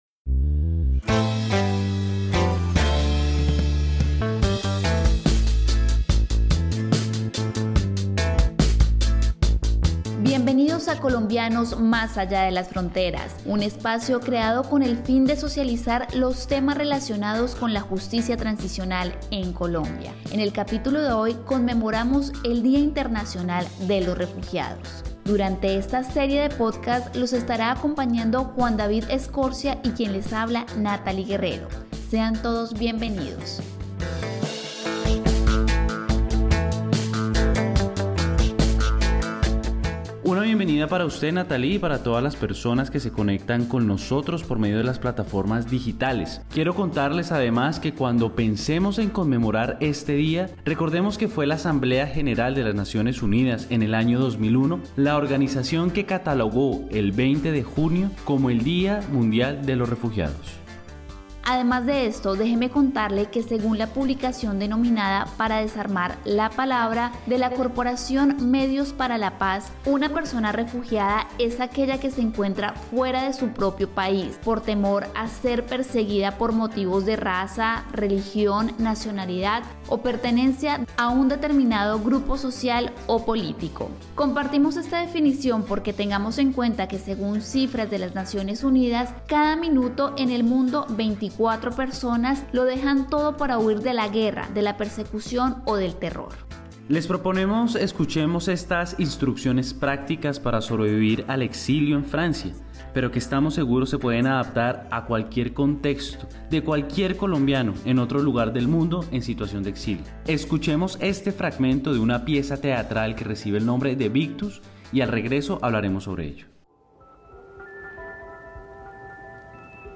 Este podcast (en español) se presenta el 20 de junio, Día Mundial de los Refugiados, decretado por la Asamblea General de las Naciones Unidas. Nos vincula con el refugio, el exilio, gracias a relatos cercanos de historias de vida de colombianas y colombianos que nos regalan, en primera persona, la riqueza de su testimonio.